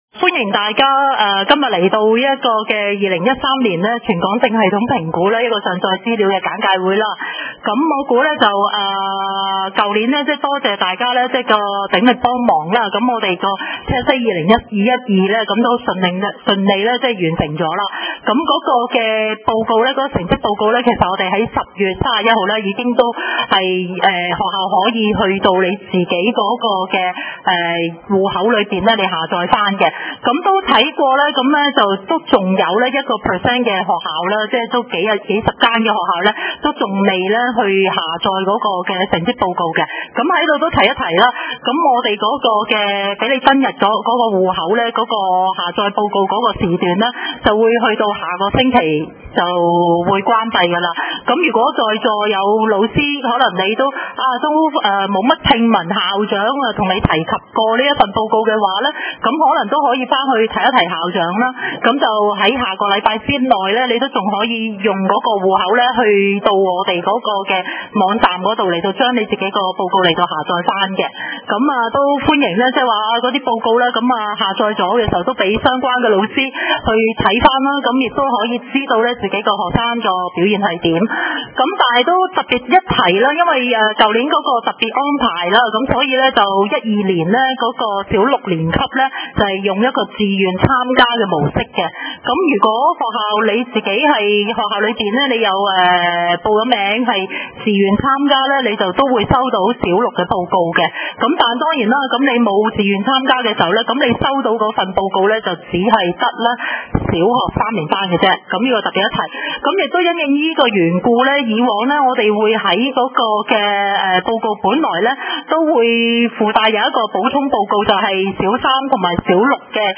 地點 ： 聖公會基福小學 下載現場錄音檔案